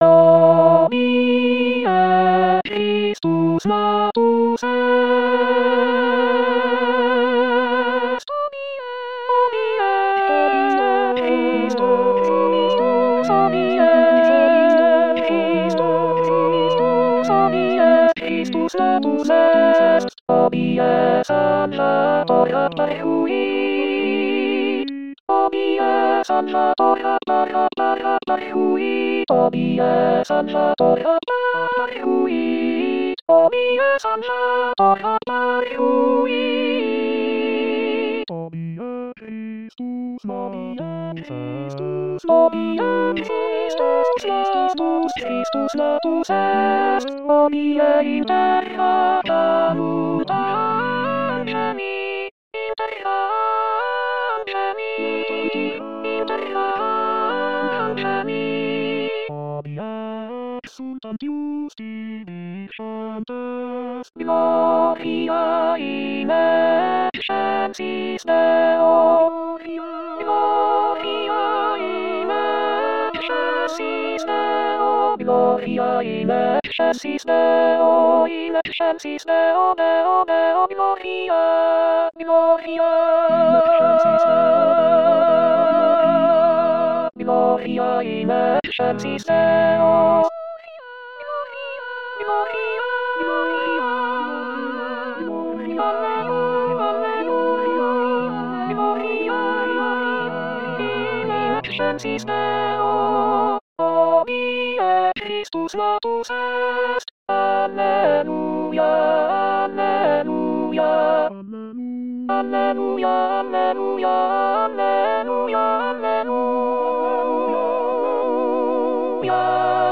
Alto Alto 2